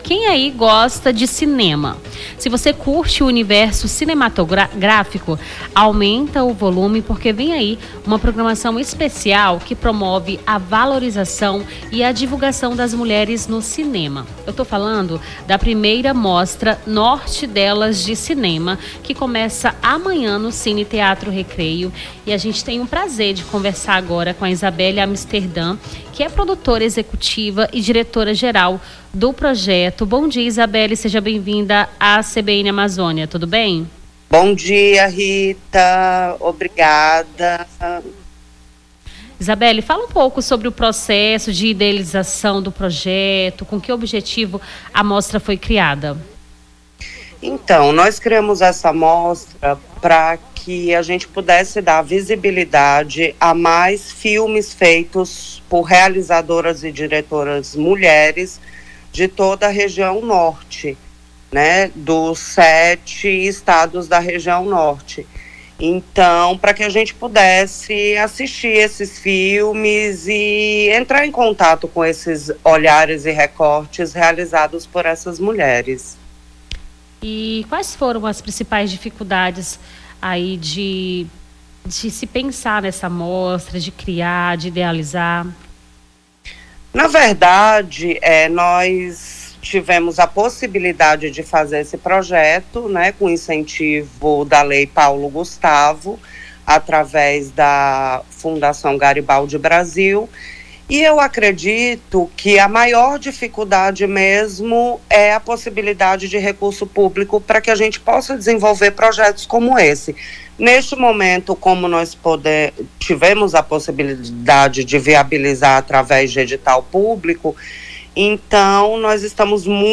Nome do Artista - CENSURA - ENTREVISTA MOSTRA NORTE DELAS (31-03-25).mp3